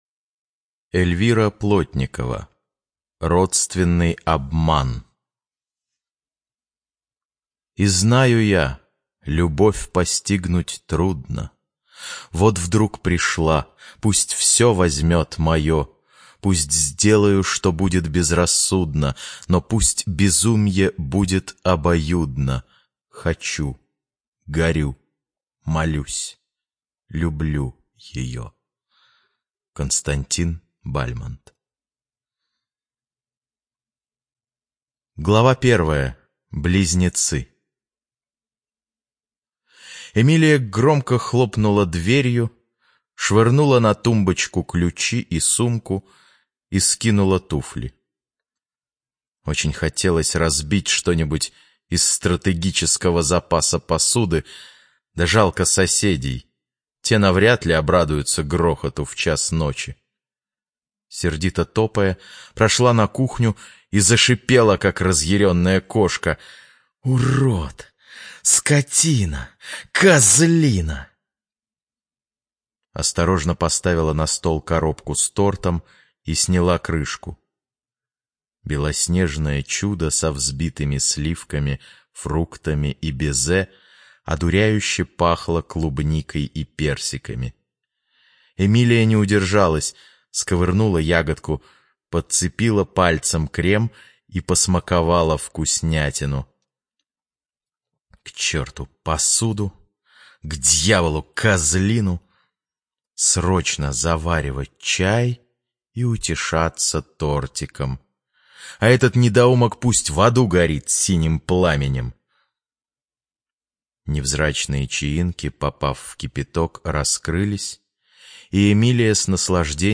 Жанр: Любовное фэнтези